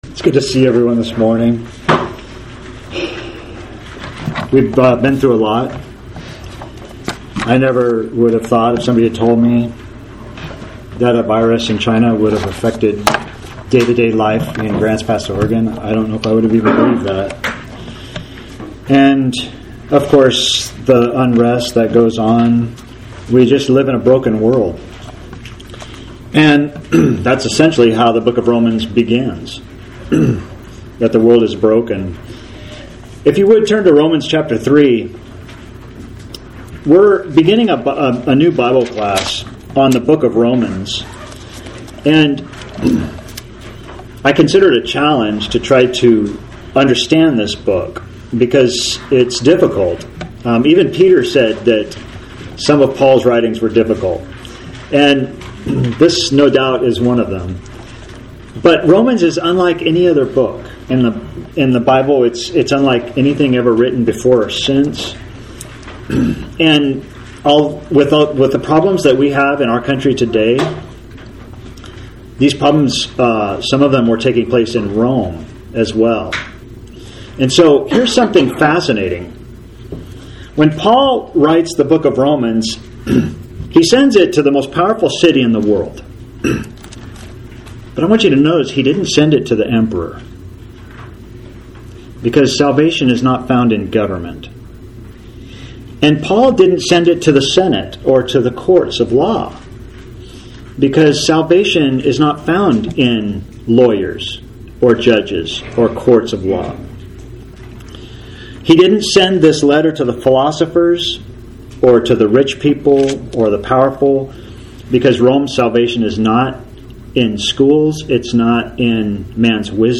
NOTE: Audio from Sunday’s sermon, video is a pre-recorded version.